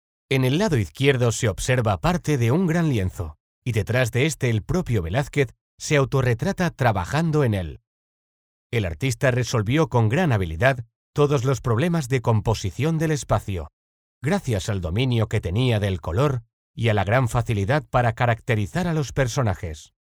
I have mi own home studio in which I do the work in the shortest possible time.
My vocal color is professional, youth, warm, persuasive and friendly.
Sprechprobe: Sonstiges (Muttersprache):
I am a professional neutral Spanish voice artist.